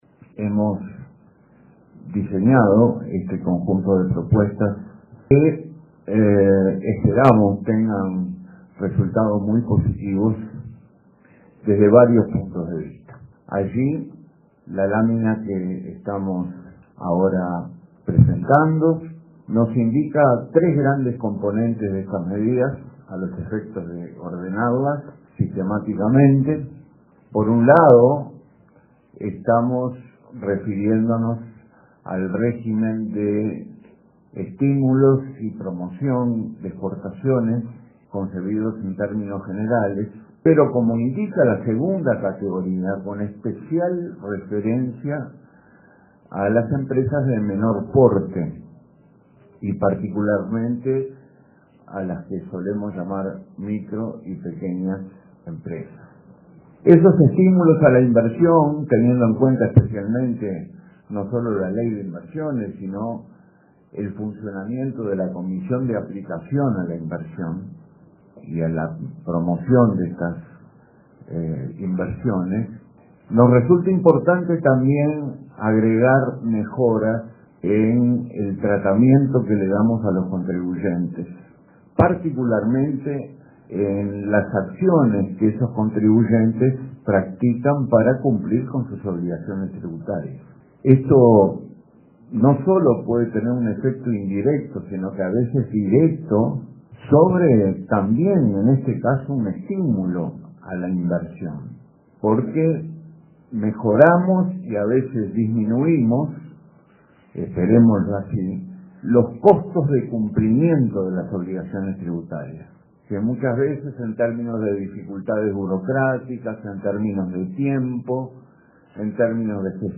Autoridades del Ministerio de Economía anunciaron un conjunto de medidas que impulsan la inversión y el empleo, con especial referencia a las pequeñas y medianas empresas. El ministro Danilo Astori dijo que resulta muy importante también agregar mejoras en el tratamiento que se da a los contribuyentes en las acciones que practican para cumplir con sus obligaciones tributarias.